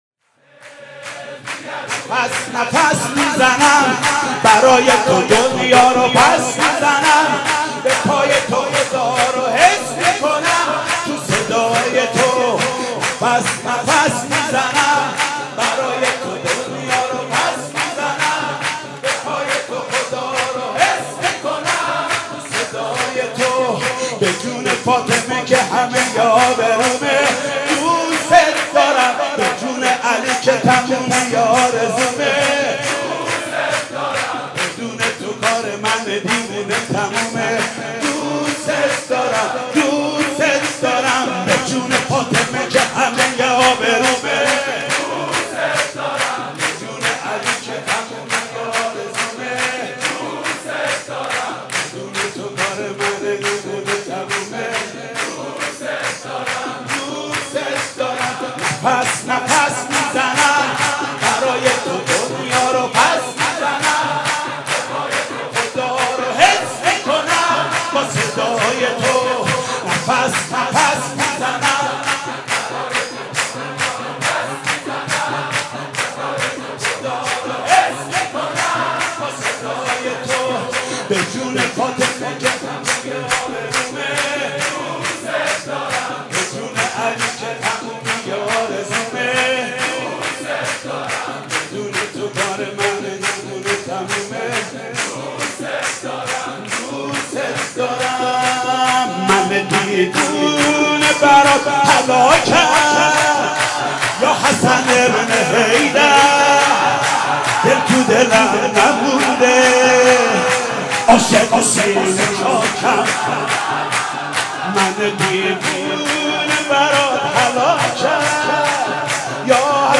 شعر و سرود (خوشا دلی که بمیرد برای نام علی